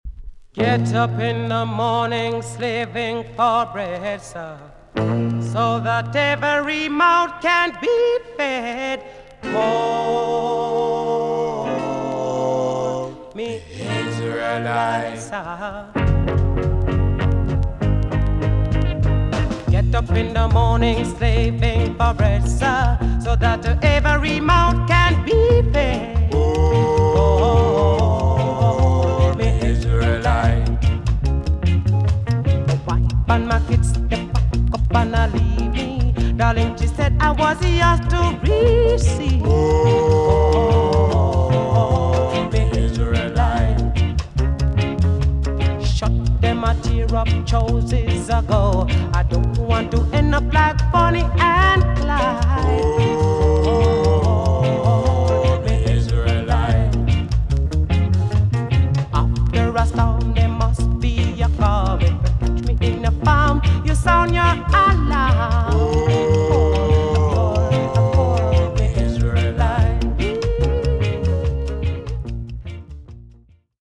TOP >REGGAE & ROOTS
EX-~VG+ 少し軽いチリノイズがありますが良好です。